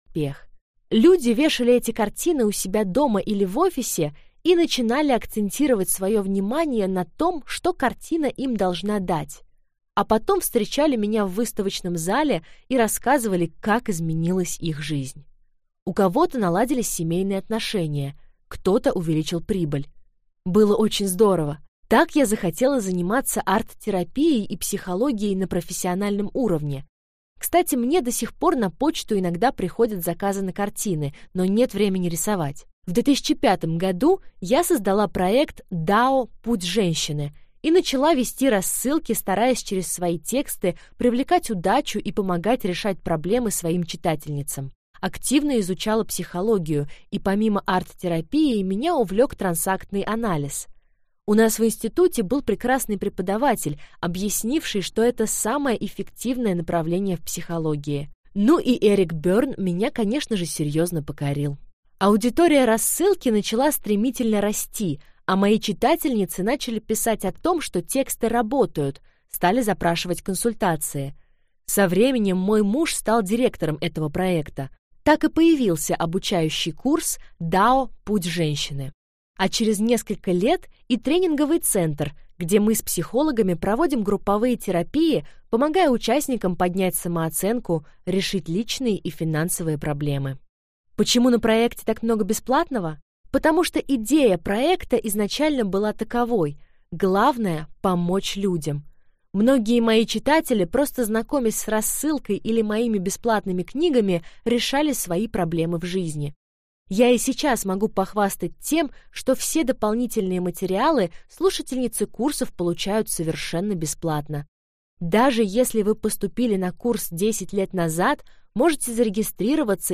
Аудиокнига На меньшее не согласна! Методика здоровой самооценки | Библиотека аудиокниг